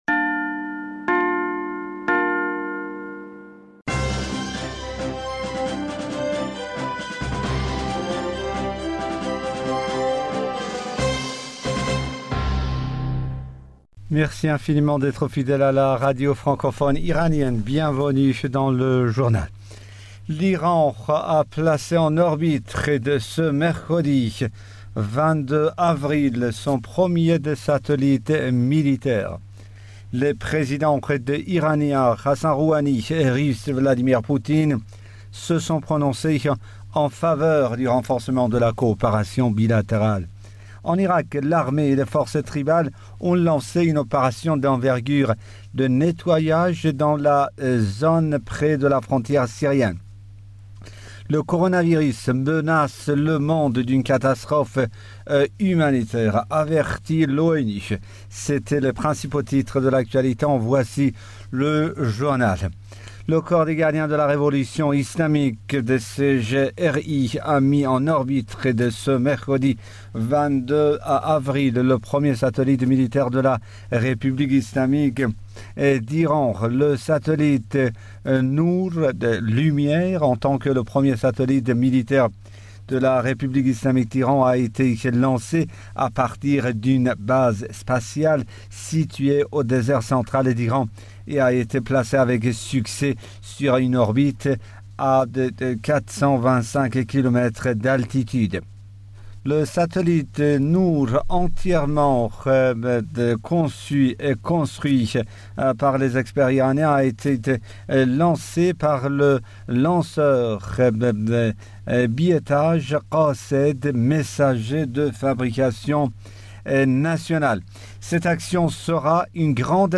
Bulletin d'information du 22 avril 2020